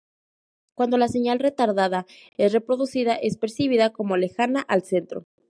Pronounced as (IPA) /seˈɲal/